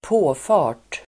påfart substantiv, slipway (on to a motorway)Uttal: [²p'å:fa:r_t] Böjningar: påfarten, påfarterDefinition: väg som leder upp på motorväg